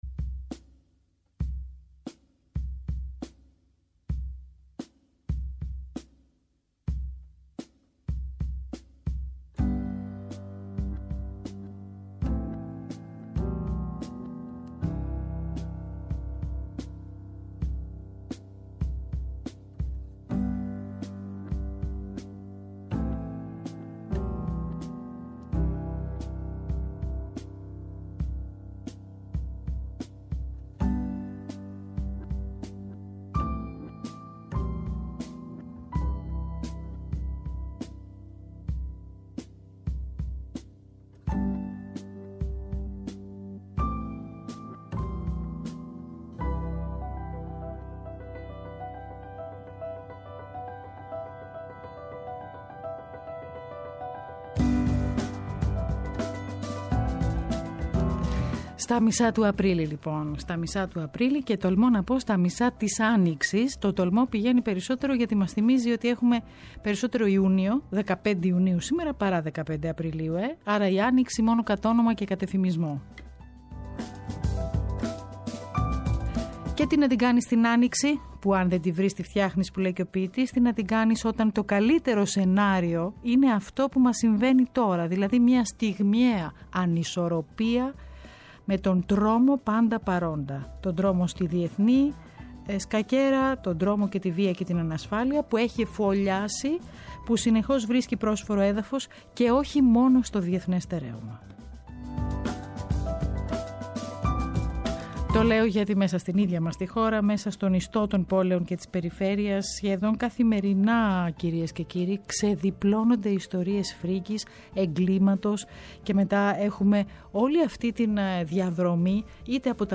Καλεσμένος στο στούντιο ο Ευτύχης Φυτράκης εγκληματολόγος, πρώην γενικός γραμματέας δικαιοσύνης για το βιβλίο του «SEX ΝΟΜΟΣ ΕΓΚΛΗΜΑ» (εκδ. Τόπος).